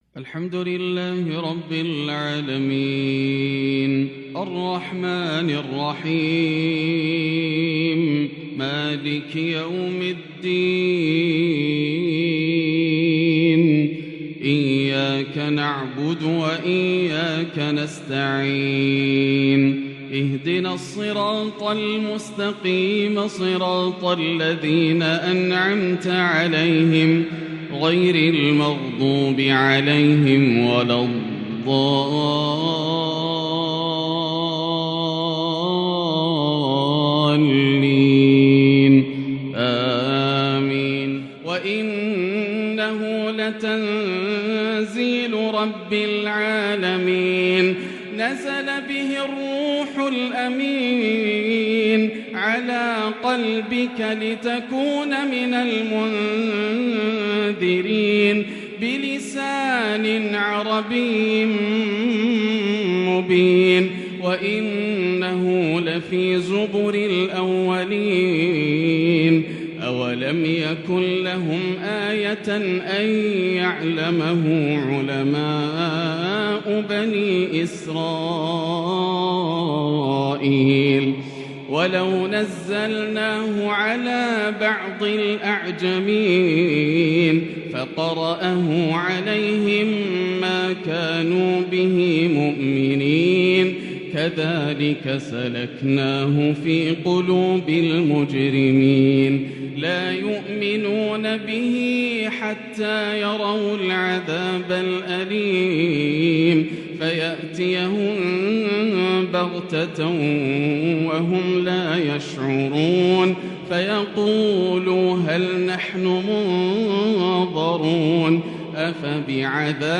“وقال يا أسفى على يوسف” تلاوة عجيبة بأداء يذهل العقول لغريد الحرم د.ياسر الدوسري > تلاوات عام 1443هـ > مزامير الفرقان > المزيد - تلاوات الحرمين